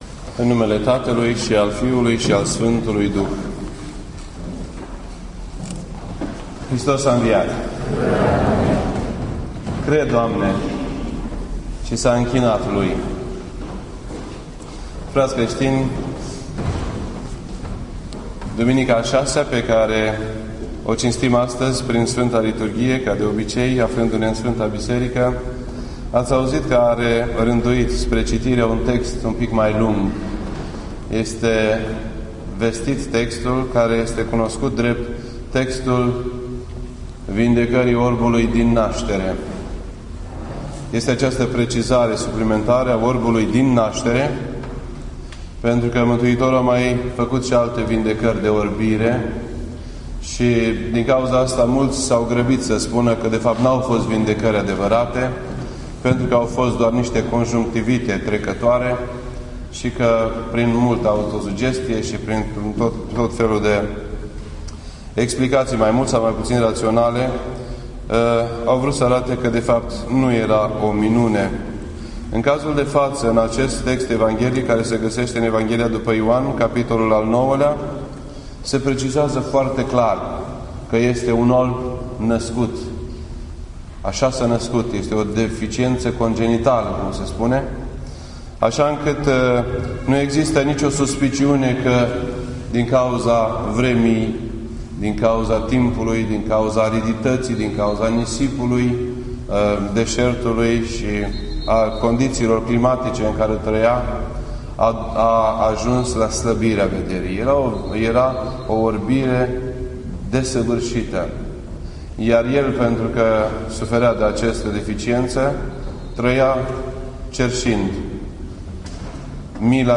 Predica la Duminica Orbului din Nastere http